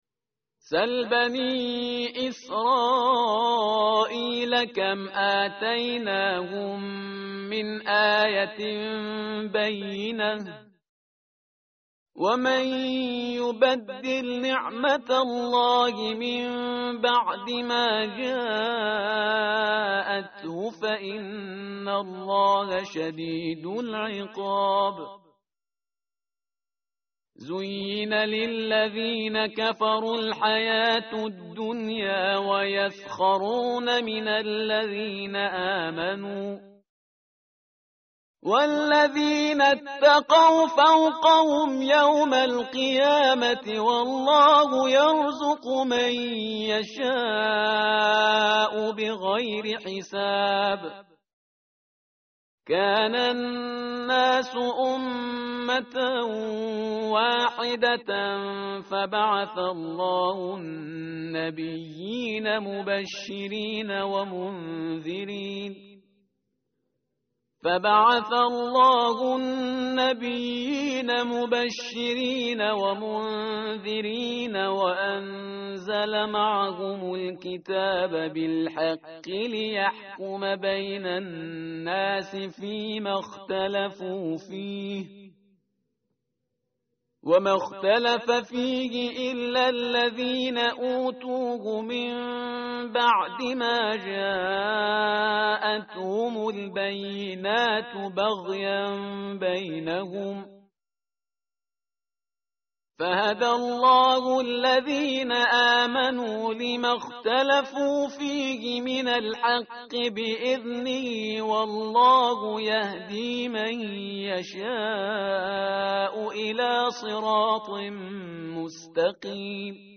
متن قرآن همراه باتلاوت قرآن و ترجمه
tartil_parhizgar_page_033.mp3